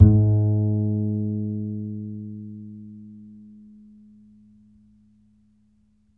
DBL BASS A#2.wav